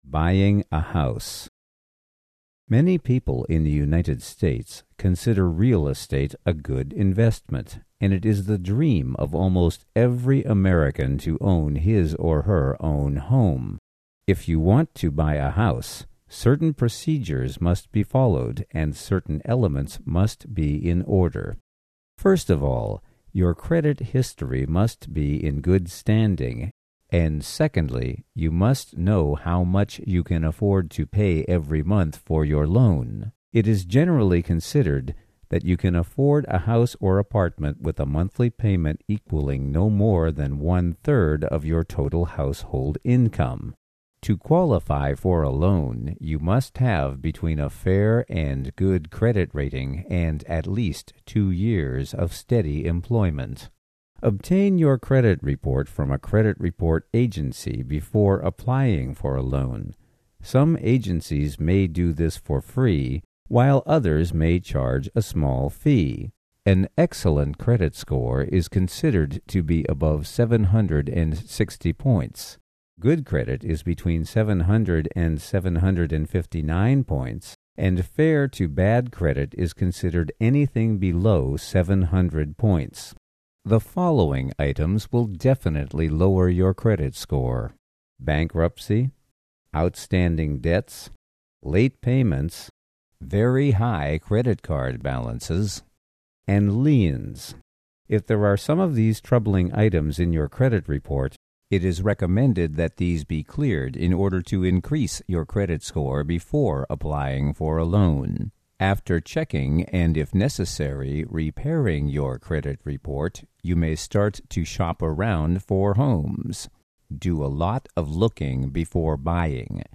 Readings